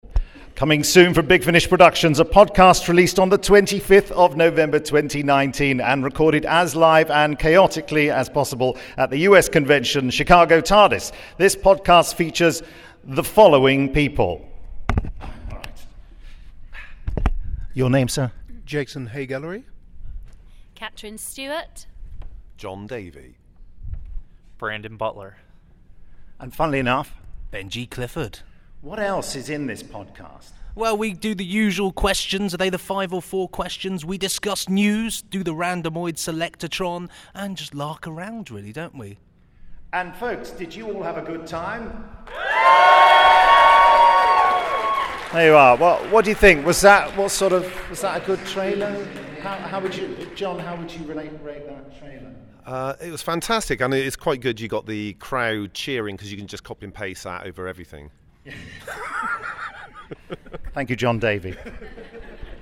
1848. Big Finish Podcast 2018-11-25 Chicago TARDIS Live - The Big Finish Podcast - Big Finish